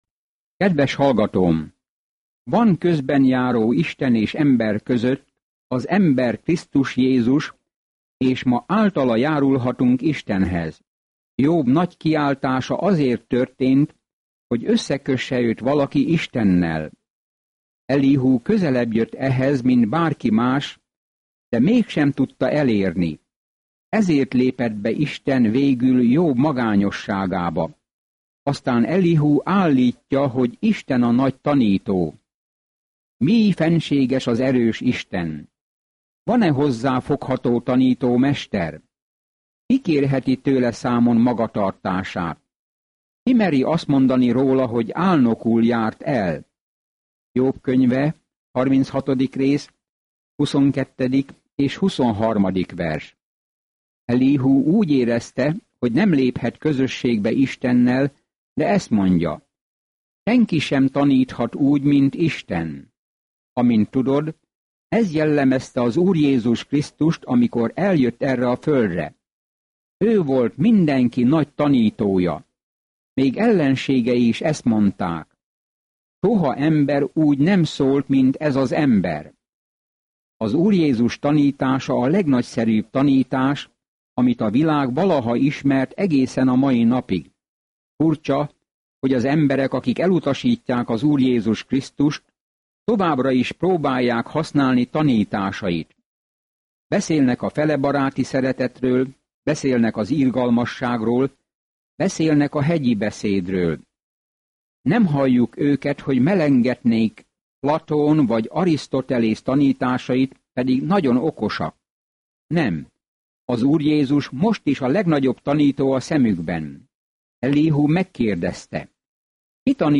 Naponta utazz Jóbon, miközben hallgatod a hangos tanulmányt, és olvasol válogatott verseket Isten szavából.